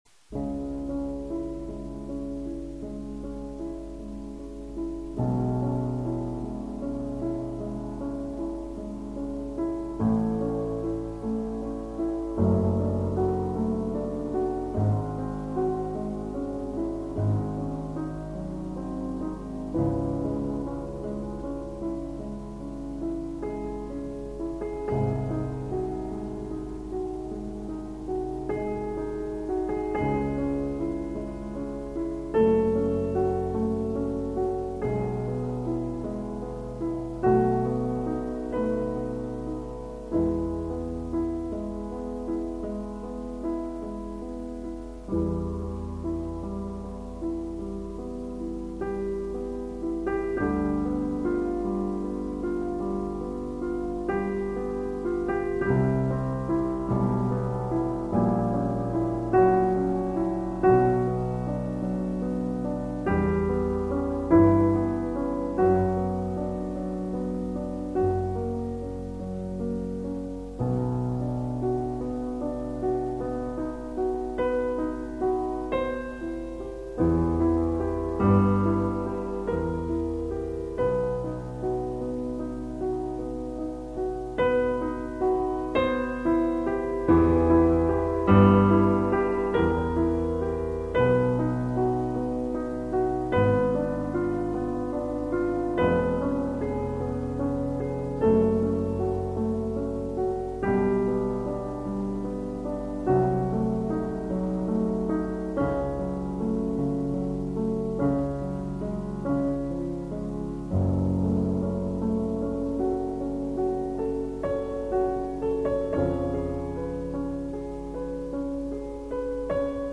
A very classical music. vuvuzela